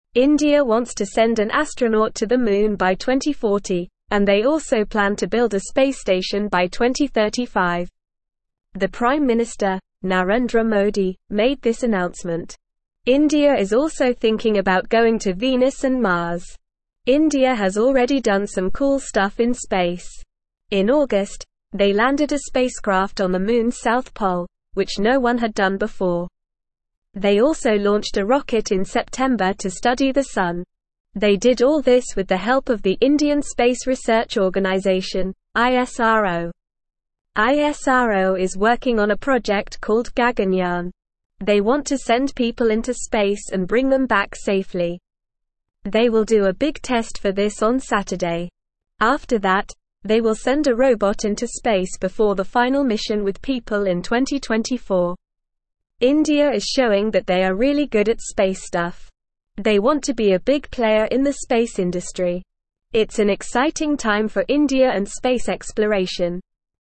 Normal
English-Newsroom-Upper-Intermediate-NORMAL-Reading-Indias-Ambitious-Space-Goals-Moon-Mars-and-More.mp3